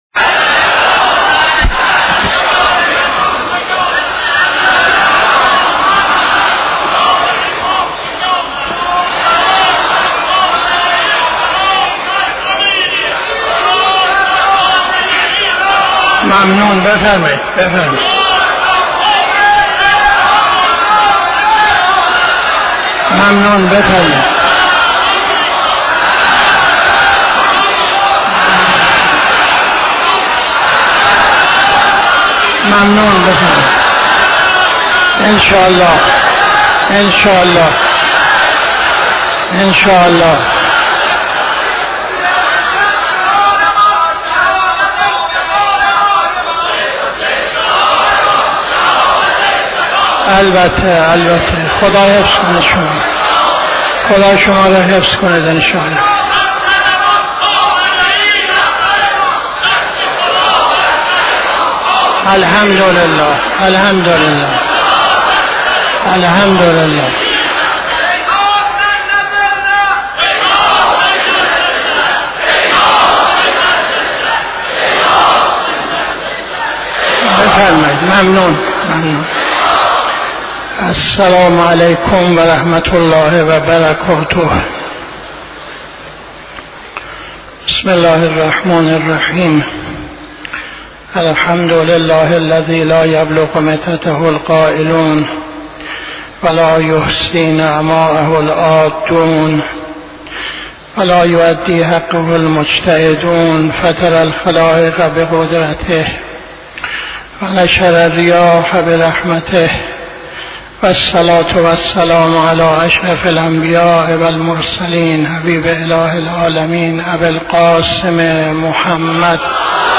خطبه اول نماز جمعه 16-12-81